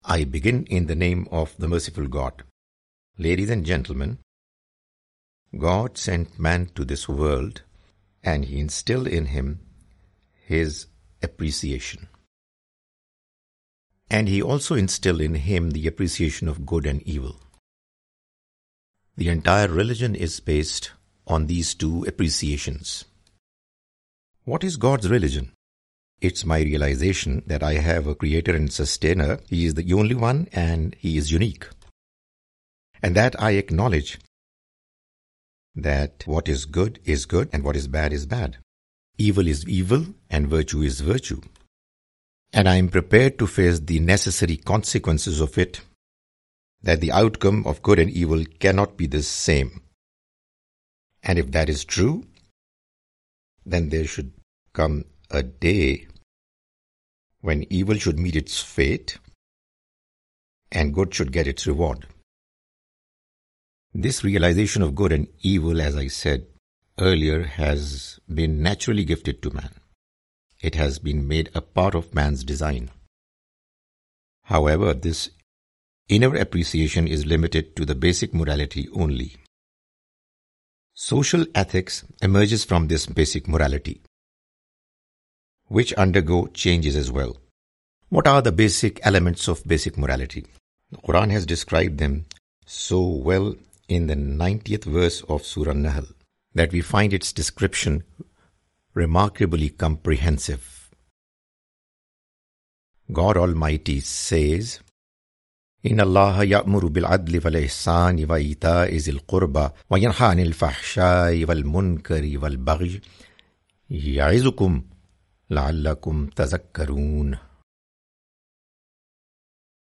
The Message of Qur'an (With English Voice Over) Part-3
The Message of the Quran is a lecture series comprising Urdu lectures of Mr Javed Ahmad Ghamidi.